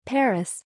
Париж — американское произношение
Американцы произносят город Париж посредством звуков «э».
Париж — Paris [ˈpəres] — пэрэс.
Paris-Amer.m4a